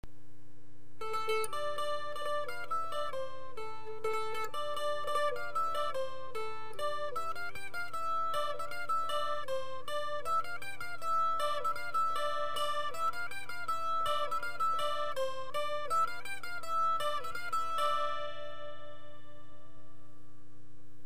Traditionnels